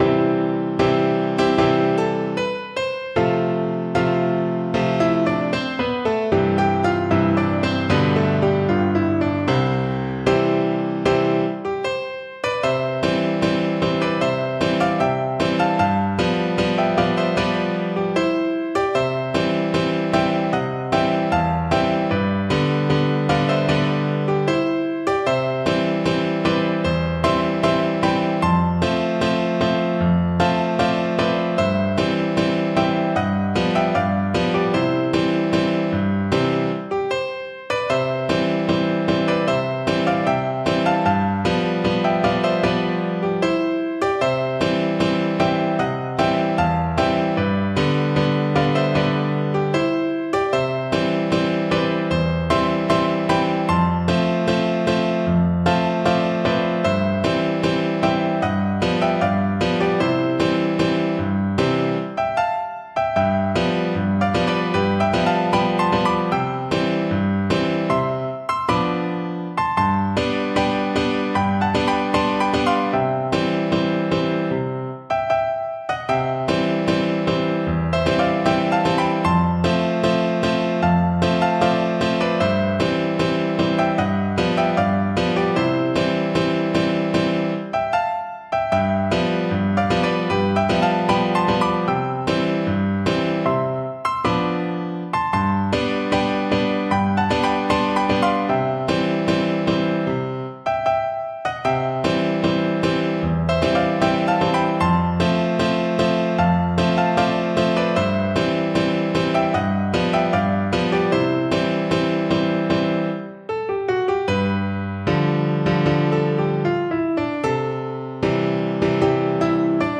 World Serradell Narciso Sevilla La Golondrina Piano version
C major (Sounding Pitch) (View more C major Music for Piano )
Moderato = 76
4/4 (View more 4/4 Music)
Piano  (View more Intermediate Piano Music)
World (View more World Piano Music)
golondrina_PNO.mp3